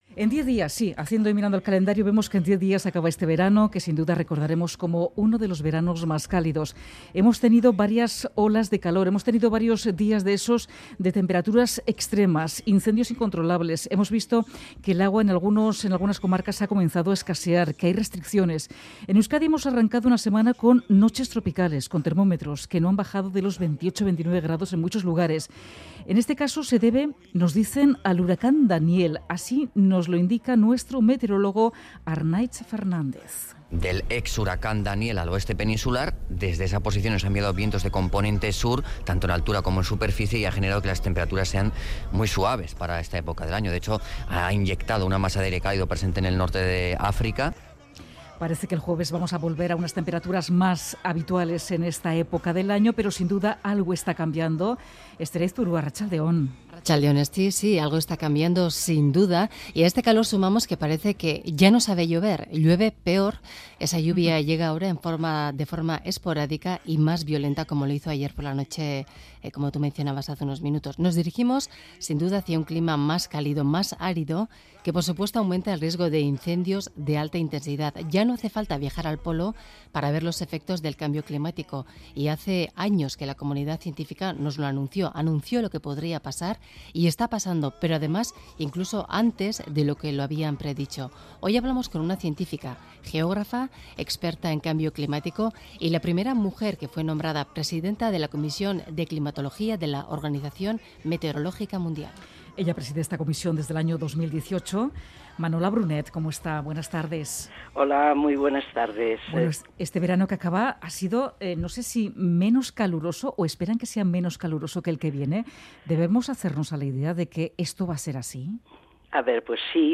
Radio Euskadi ENTREVISTAS